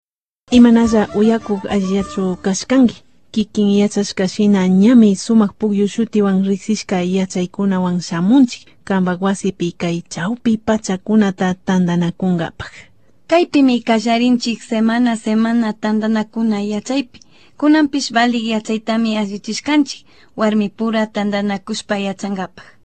Here’s a recording of part of a news report in a mystery language.